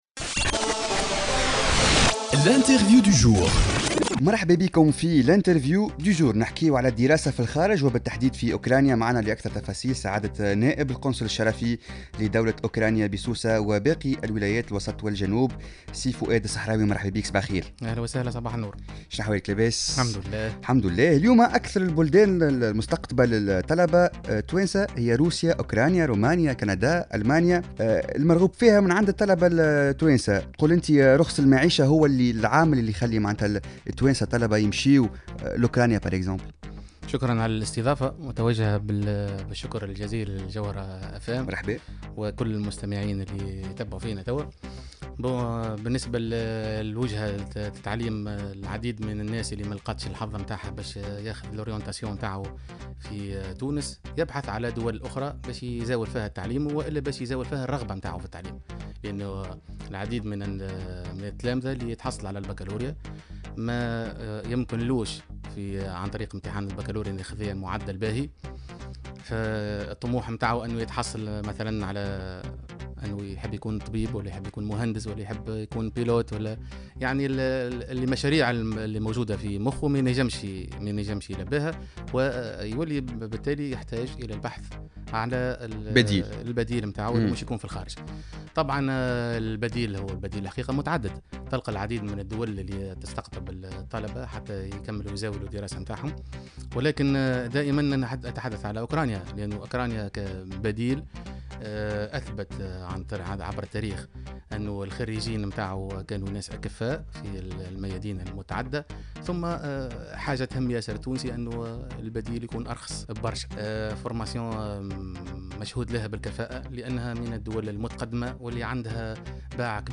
أكد نائب القنصل التونسي بأوكرانيا فؤاد صحراوي في مداخلة له على الجوهرة "اف ام" اليوم الجمعة 29 جويلية 2016 أن أوكرانيا أصبحت الوجهة الأولى للتلاميذ الناجحين في الباكالوريا والذين لم تسعفهم معدلاتهم للإلتحاق بالشعب التي أرادوها في تونس.